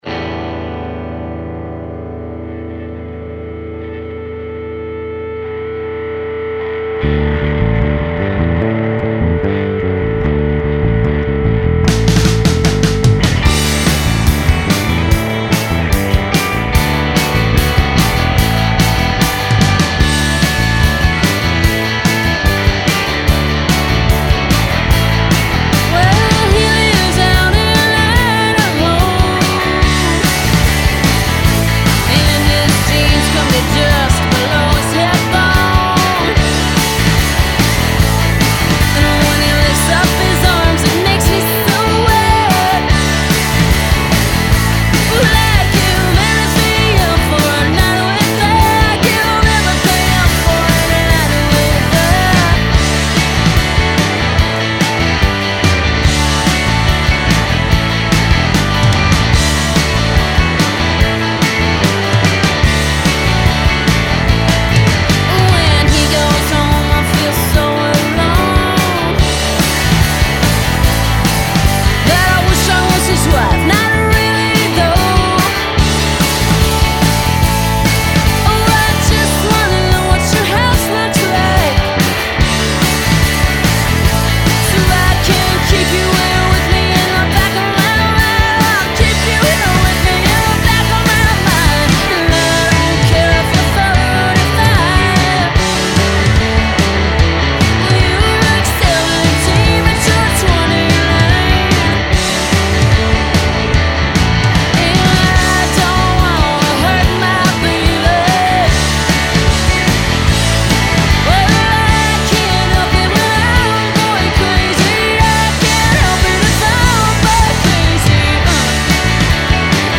Hard-edged, alt-country-flavored
hard-edged, smartly sung alt-country-flavored rock’n’roll